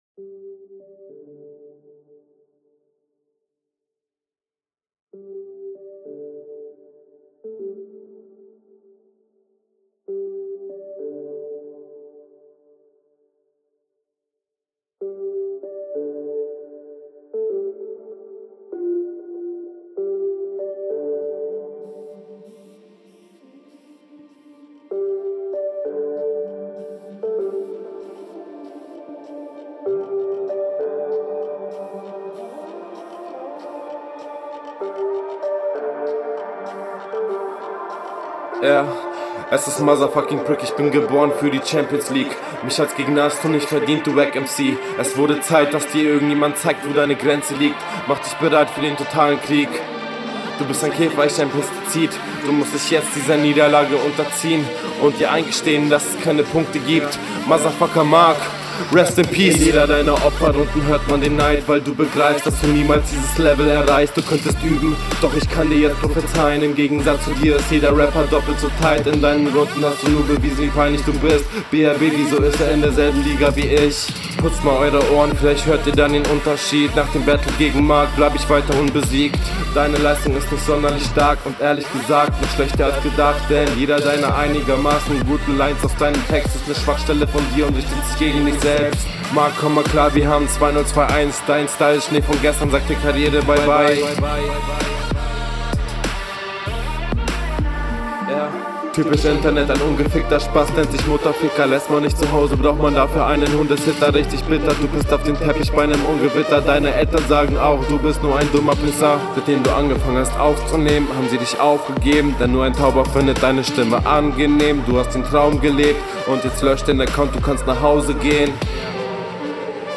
Nee der Beat passt gar nicht zu dir mMn.